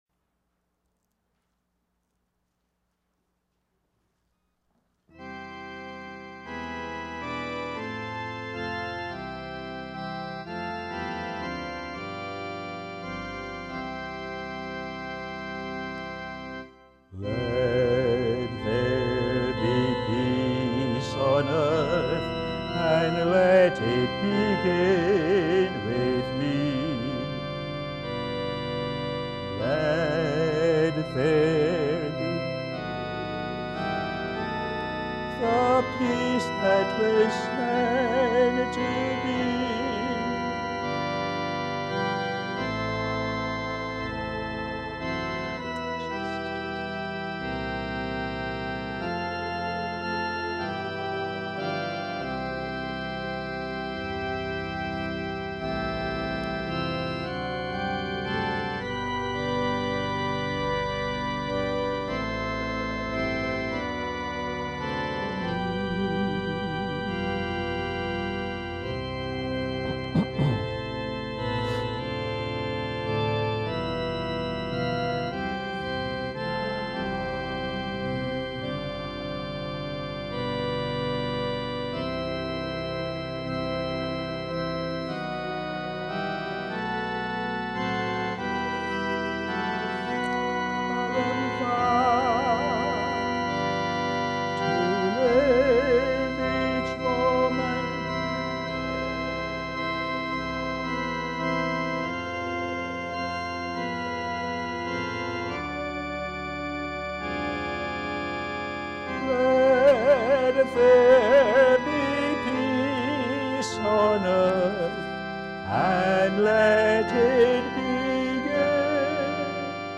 Posted in Sermons on 12. Sep, 2011